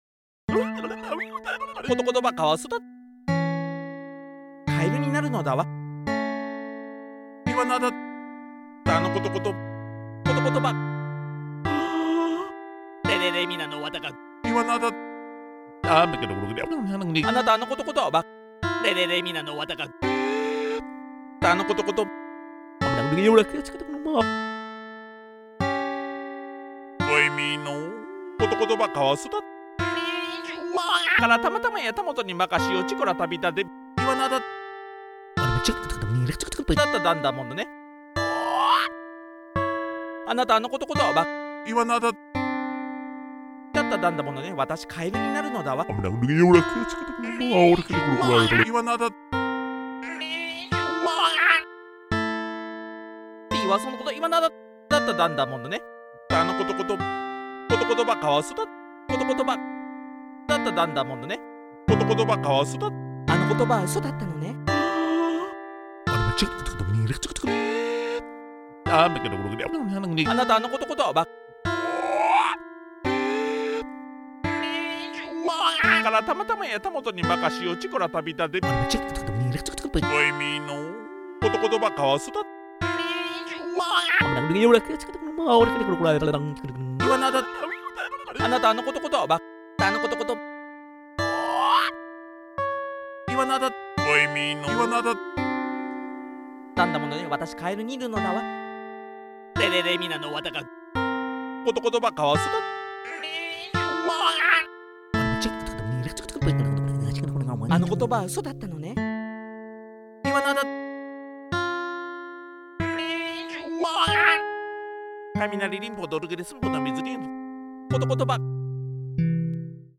音や声を大胆かつユーモラスに配置した傑作です！サウンドメイキングがポップに響く前衛作品！